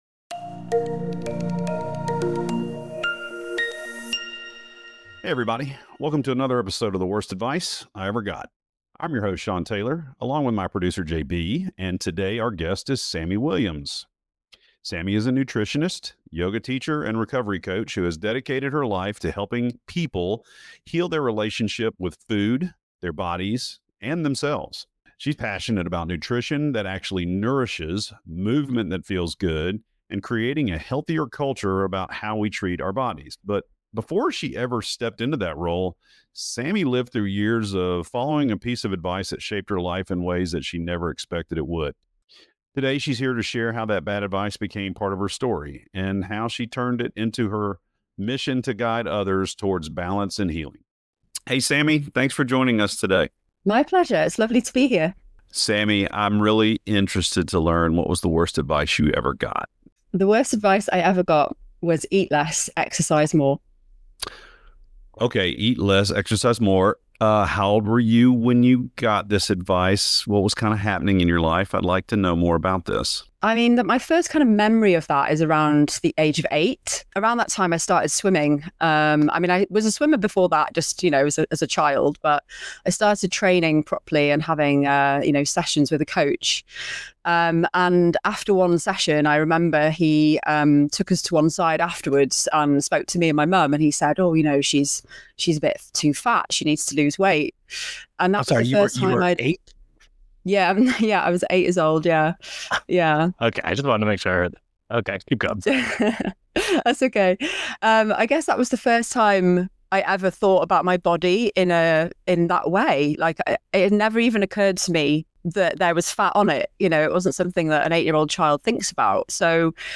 It is a candid and powerful conversation about healing, self-acceptance, and finding balance beyond the scale.